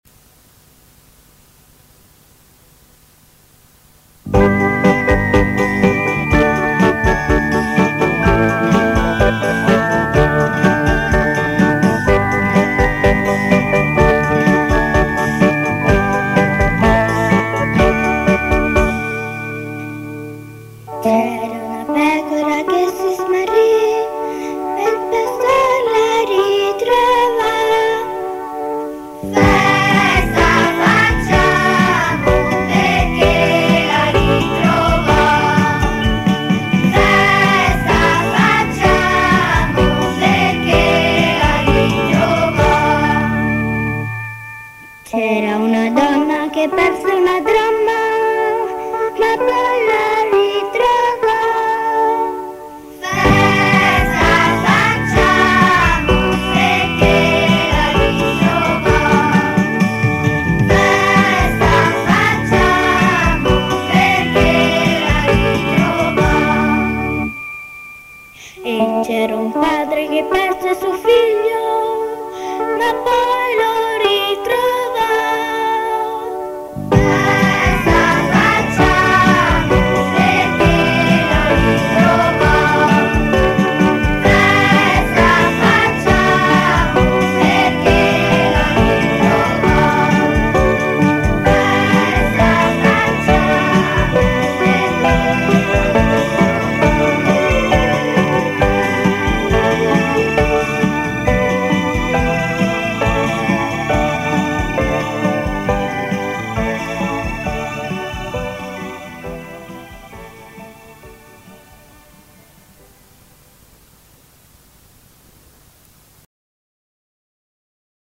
Canto per la Decina di Rosario e Parola di Dio: Festa facciamo